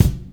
• Clear Kick Single Shot B Key 403.wav
Royality free bass drum single shot tuned to the B note. Loudest frequency: 642Hz
clear-kick-single-shot-b-key-403-2uV.wav